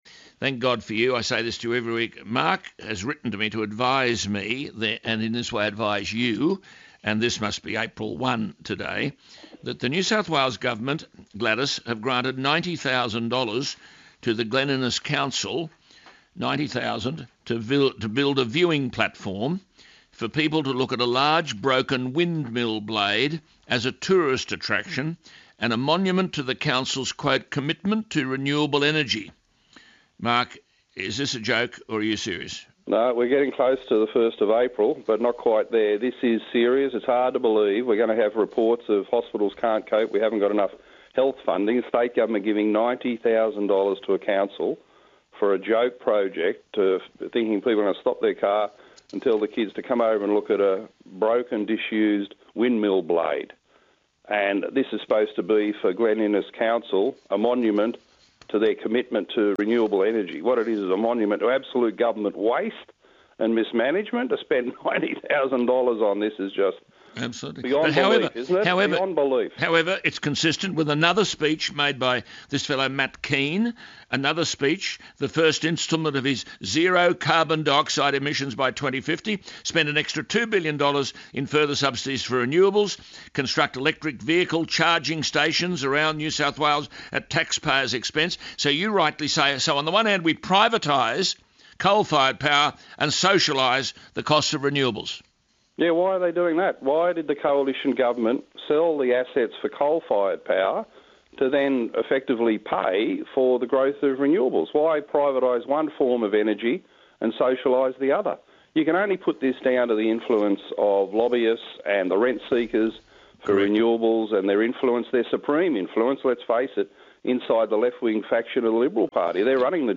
Mr Latham tells Alan Jones the NSW Government has granted $90,000 to Glen Innes Council to build a viewing platform for people to look at a large broken windmill blade.